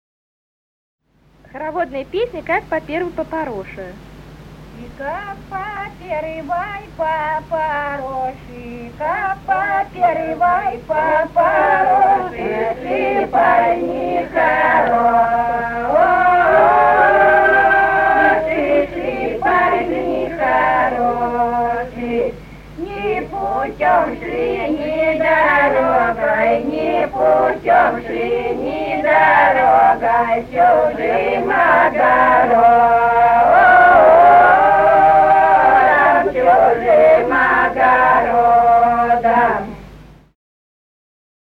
Русские народные песни Владимирской области [[Описание файла::9. Как по первой по пороше (хороводная) стеклозавод «Красный химик» Судогодского района Владимирской области.